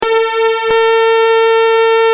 Free MP3 vintage Sequential circuits Pro-5 loops & sound effects 2